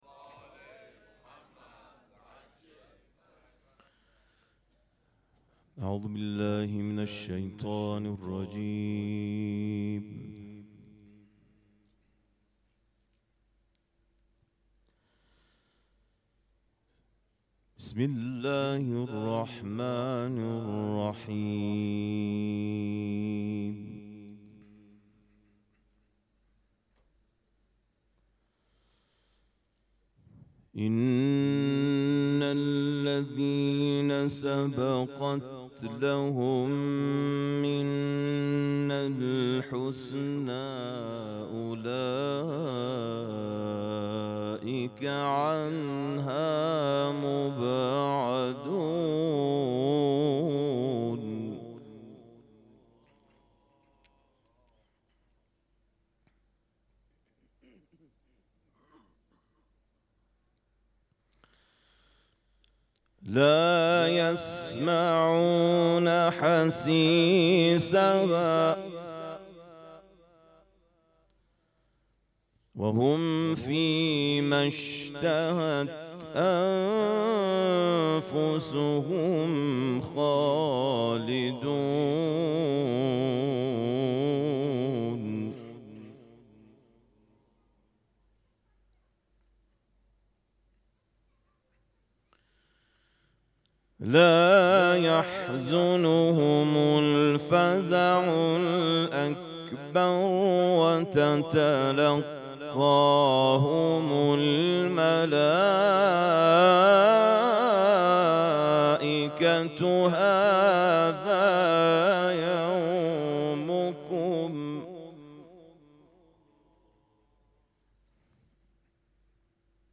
مراسم عزاداری شب شهادت حضرت رقیه سلام الله علیها
قرائت قرآن